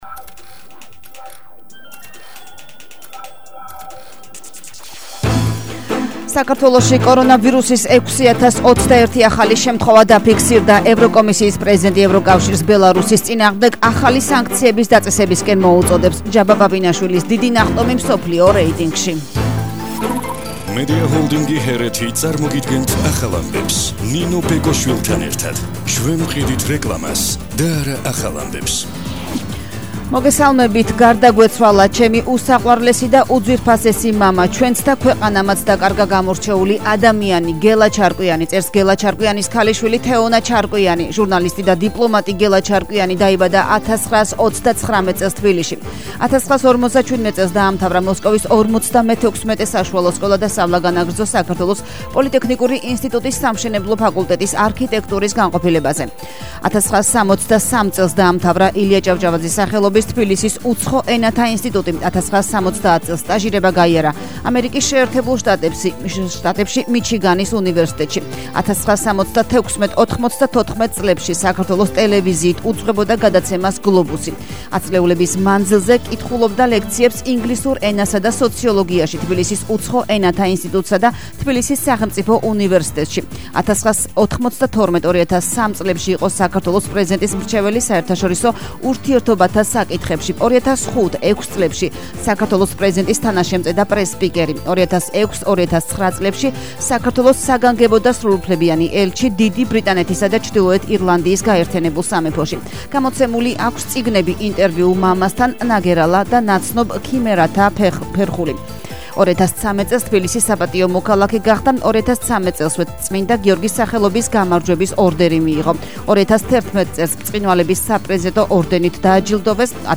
ახალი ამბები 12:00 საათზე –9/11/21 - HeretiFM